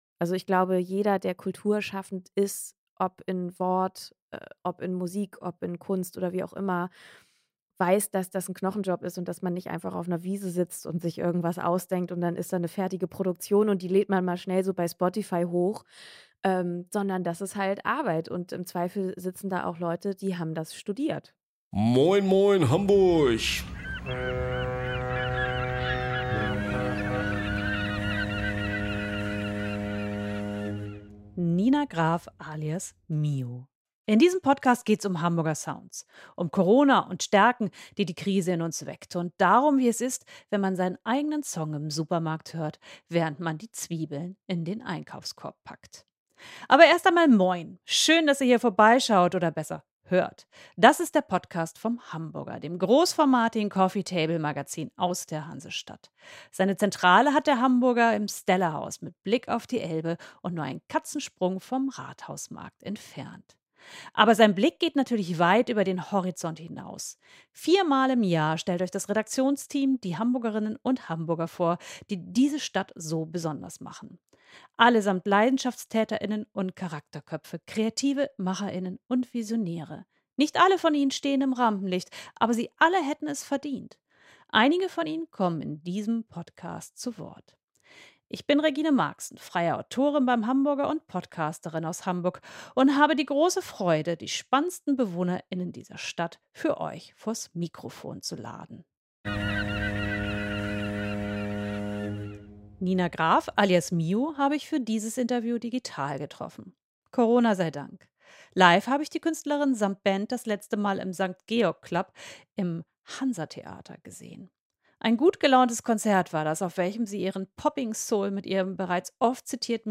Digital. Corona sei Dank.